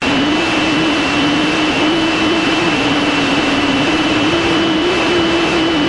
我的第一个 "法兰西鼓 "120bpm
描述：在Ableton喋喋不休，想出了这个